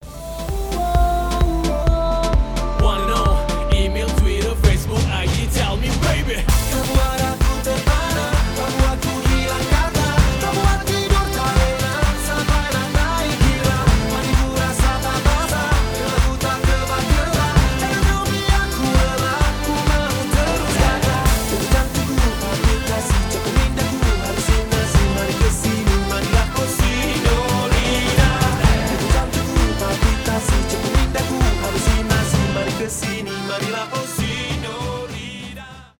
танцевальные , зажигательные
dancehall